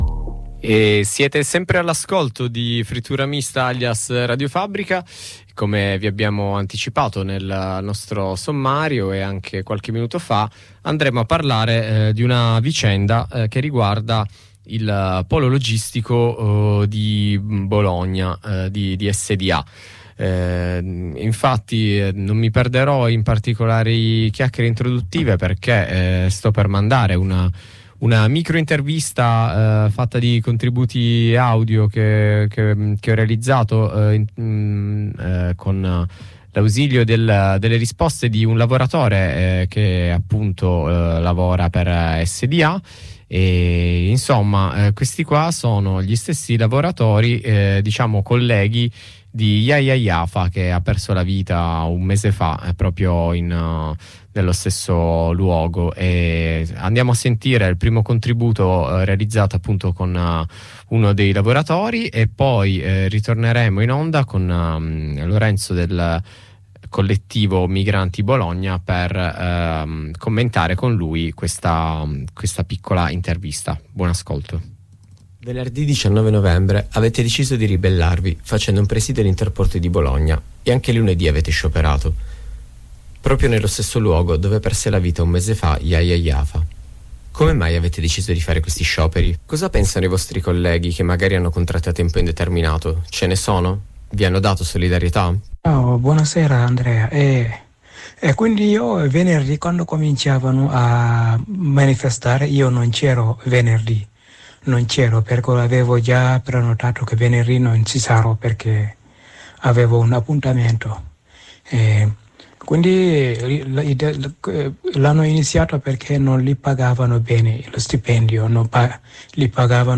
F_m_23_11_Scioperi-SDA-intervista-a-lavoratore-e-a-coordinamento-migranti-BO.mp3